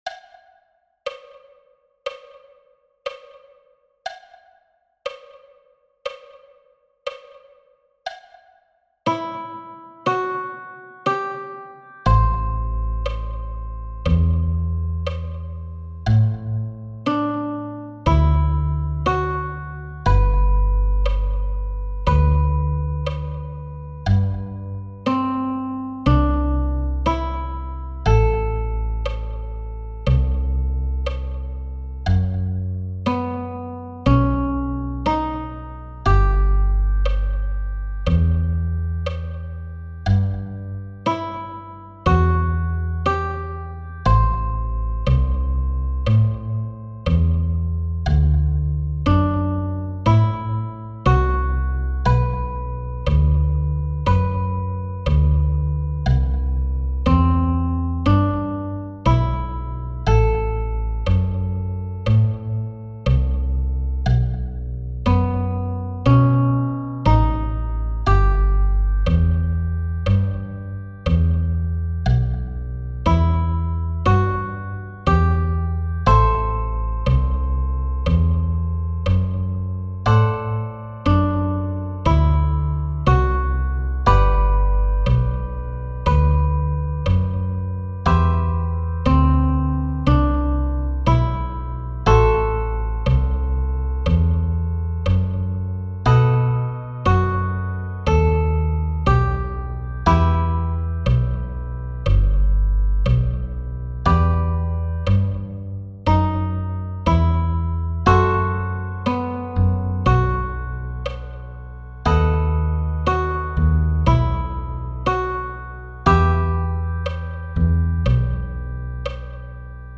.mp3 File (slow version)
Autumn_Leaves-60bpm.mp3